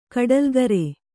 ♪ kaḍalgare